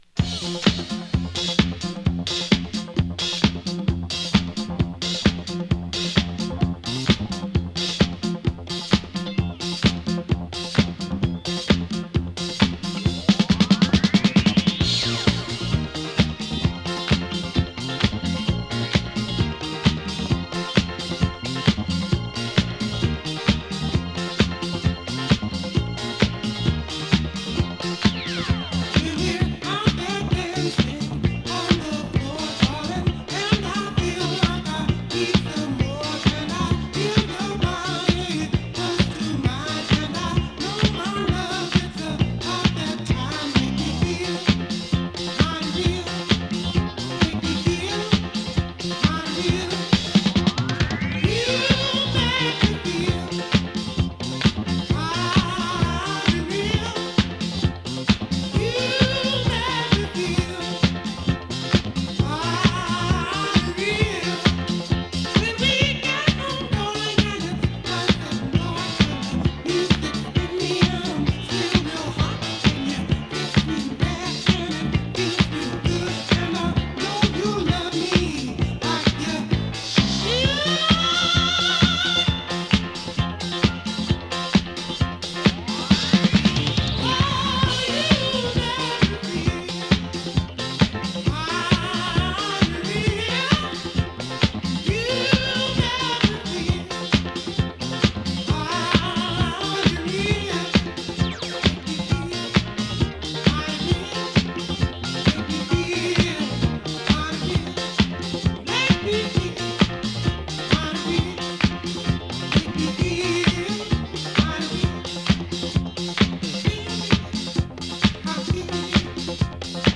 ディスコ